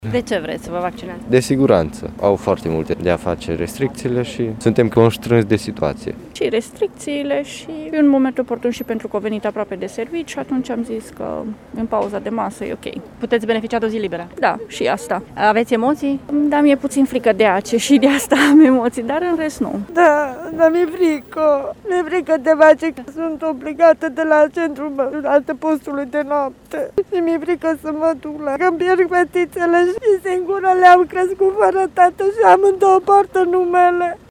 Câteva zeci de târgumureșeni s-au prezentat, încă de la debutul campaniei pentru a se vaccina iar unii recunosc că mai mult din cauza restricțiilor impuse, inclusiv câteva persoane de la Adăpostul de Noapte: